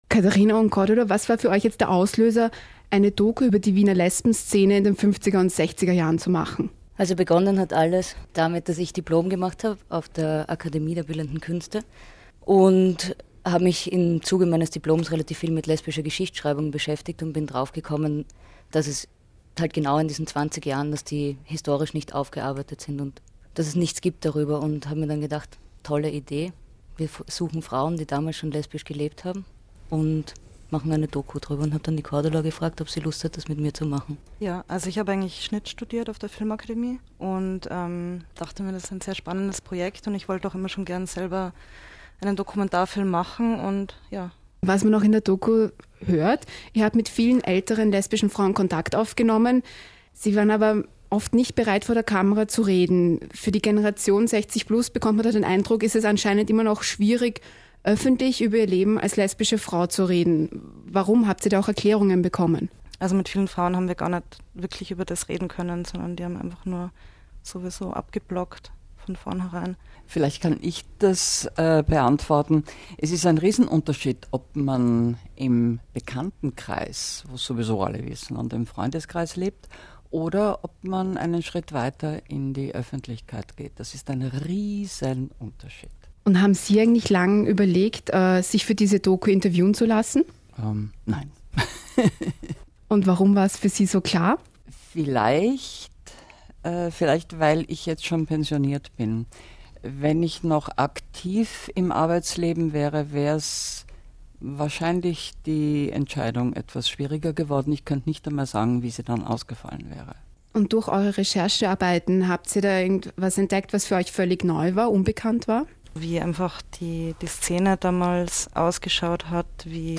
interviews.mp3